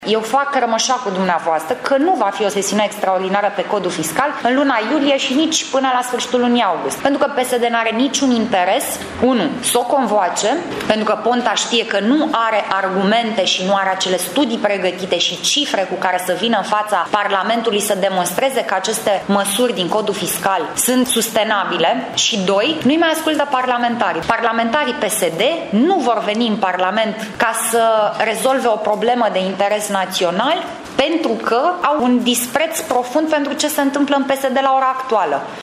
Copreședintele PNL Alina Gorghiu nu crede că se va întâmpla acest lucru deoarece nu este în interesul PSD :